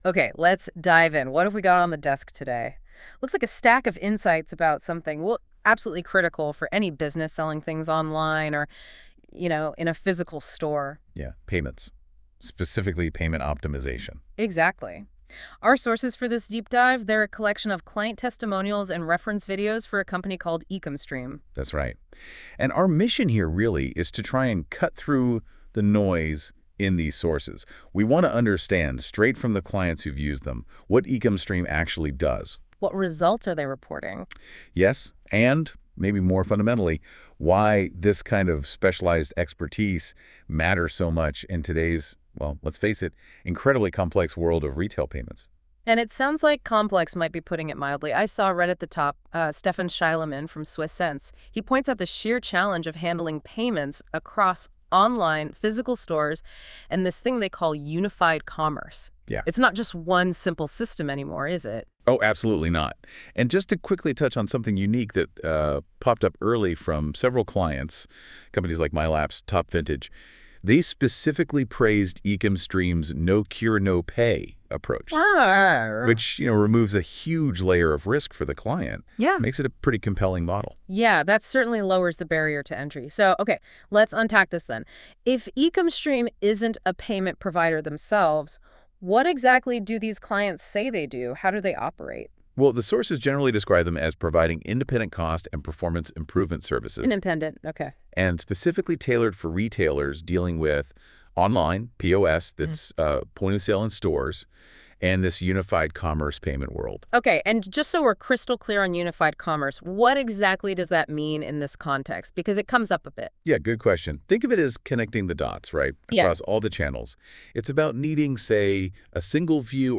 A compilation of client testimonials by NotebookLM